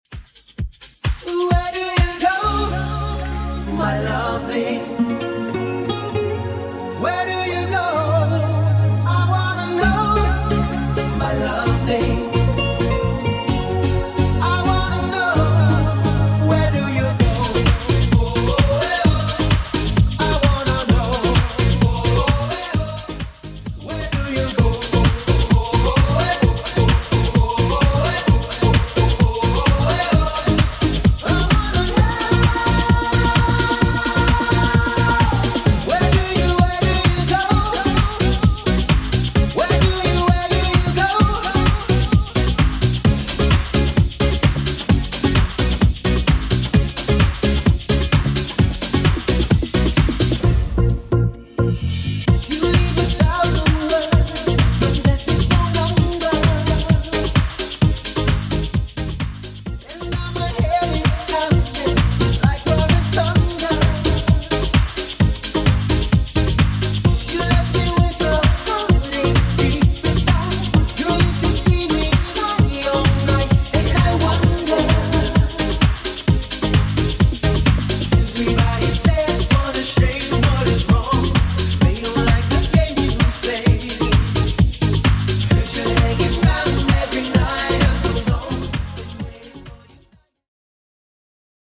Genre: 80's Version: Clean BPM: 100